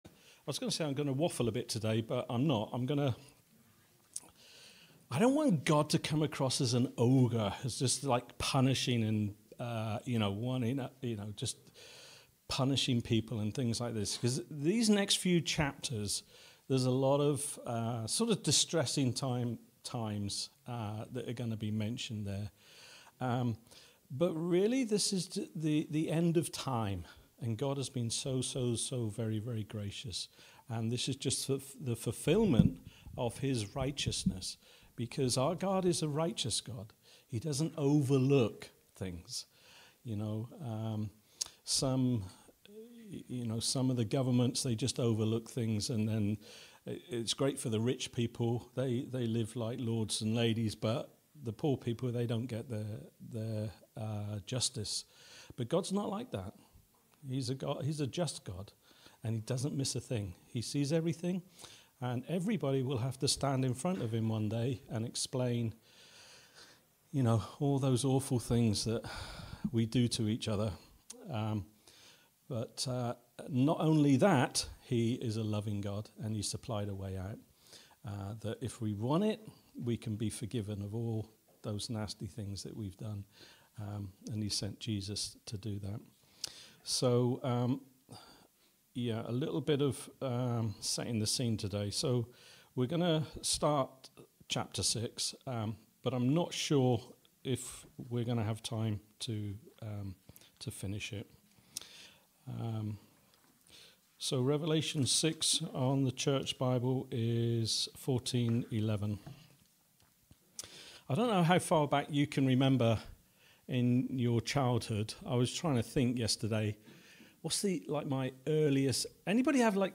A message from the series "Micah."